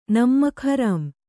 ♪ nammak harām